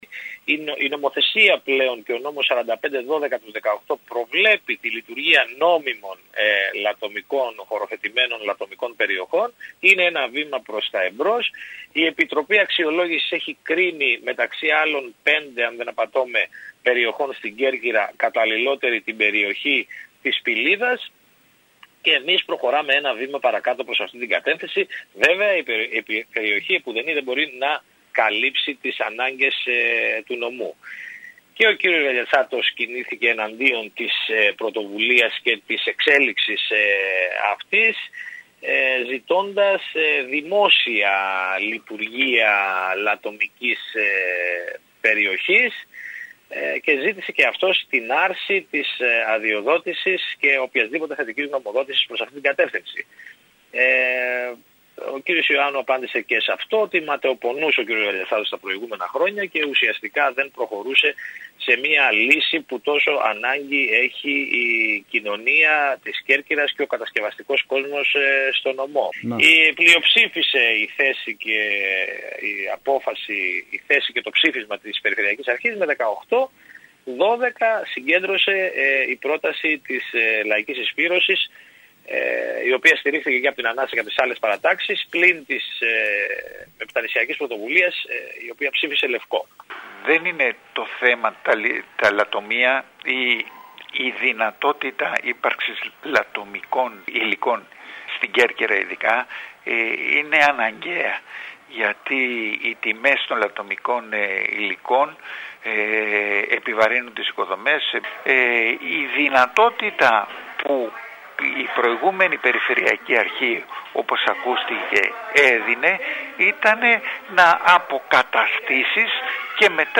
Ακούμε τον πρόεδρο του Περιφερειακού Συμβουλίου Νίκο Μουζακίτη και το μέλος της παράταξης ΑΝΑΣΑ Τηλέμαχο Γαβαλά.